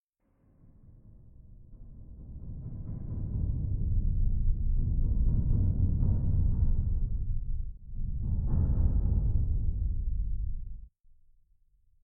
DeepRattle.mp3